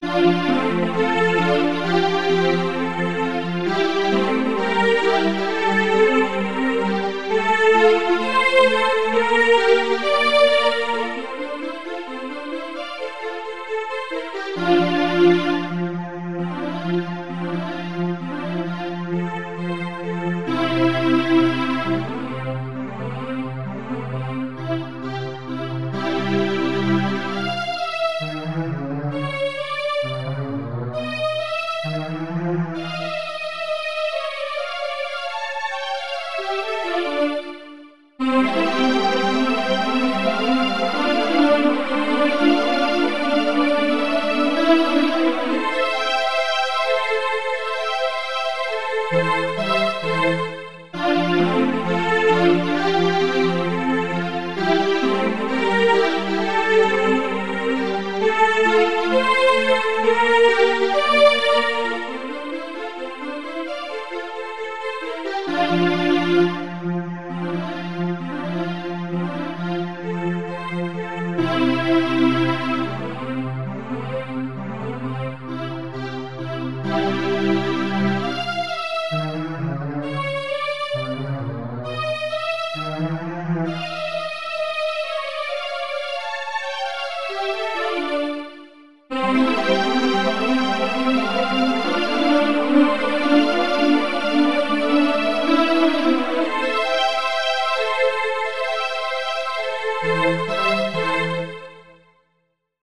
弦楽曲（未完成＝主題提示部のみ・ファイル容量：3,297kB
この曲を作る過程で、不協和音の効果に気付いてしまったのでした・・・